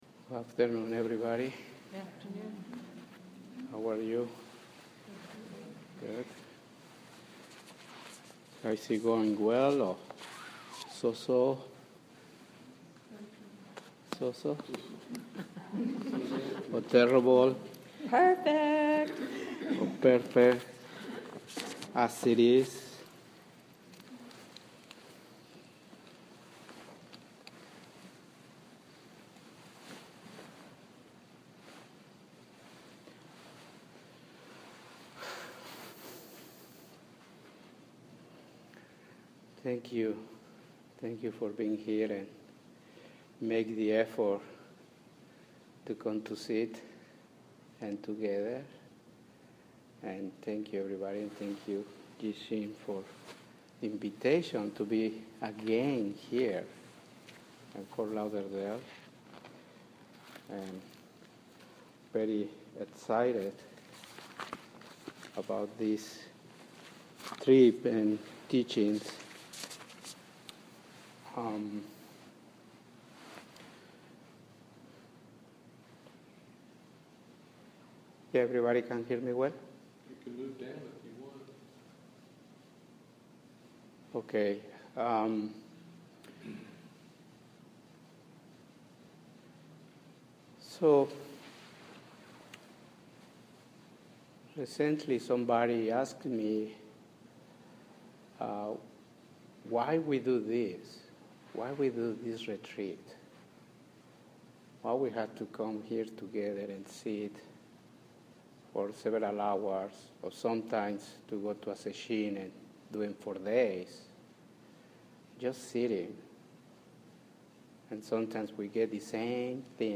Pulling Out The Roots of Suffering: Dharma Talk